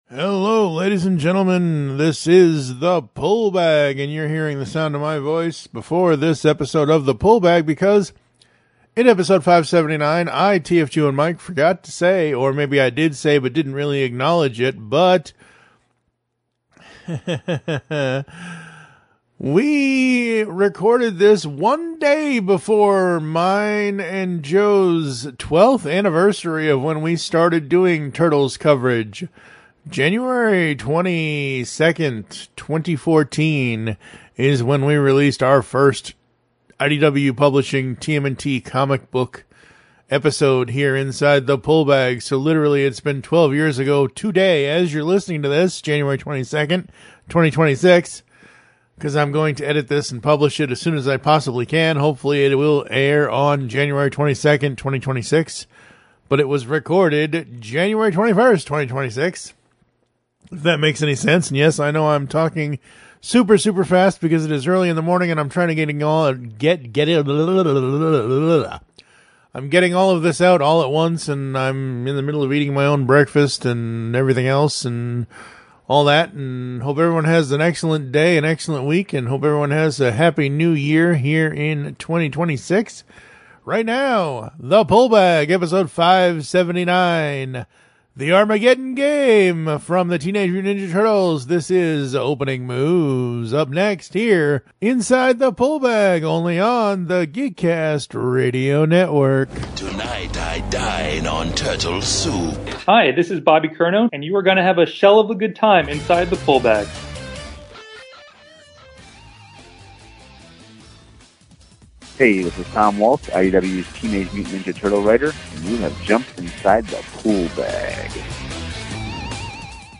Be a guest on this podcast Language: en Genres: Animation & Manga , Leisure Contact email: Get it Feed URL: Get it iTunes ID: Get it Get all podcast data Listen Now...